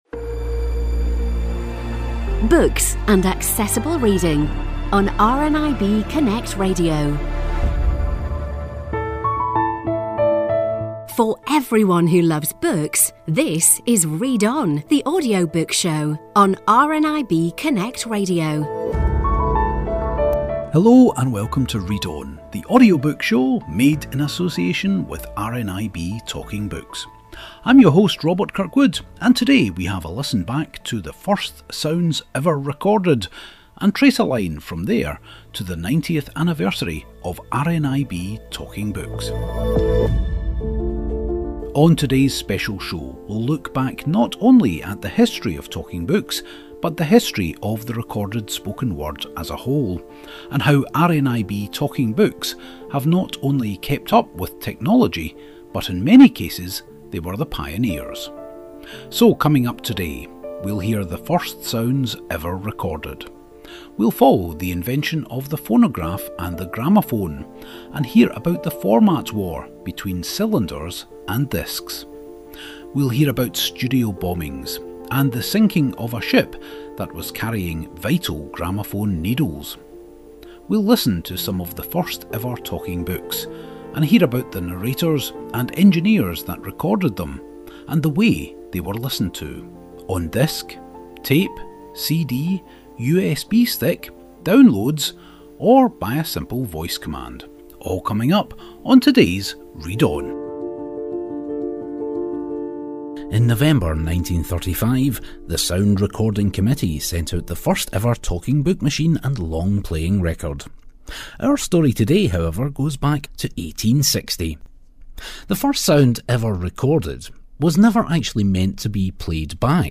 We hear the first recorded voices, about the format war between cylinder and disc, about the phonautograph, the phonograph, the graphophone and the gramophone, to single track magnetic tape, DAISY CDs and Alexa Skills and more in this 169 year trek through the history of recording the human voice.